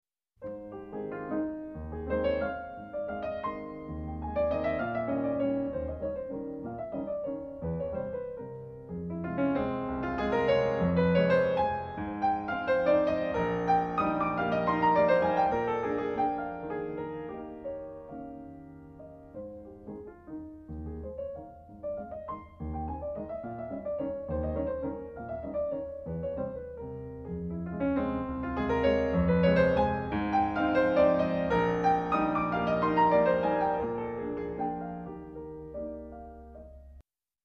A flat major